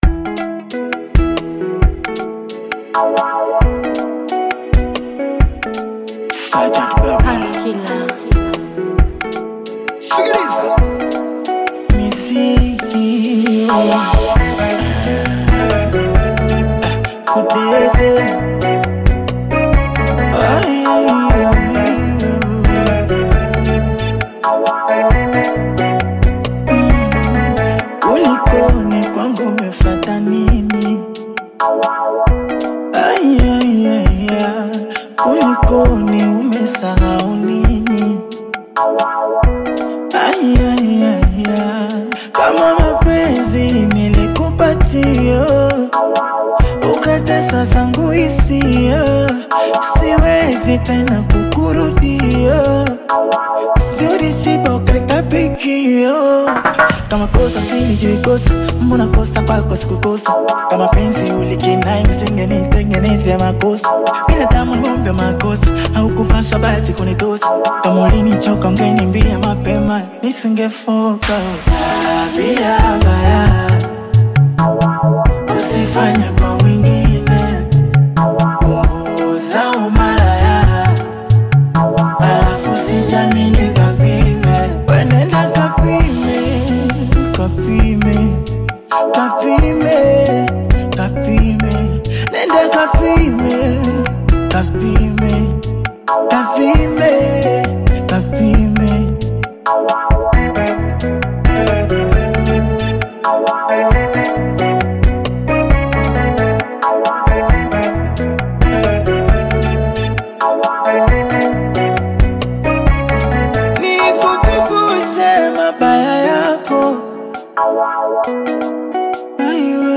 Afro-Beat single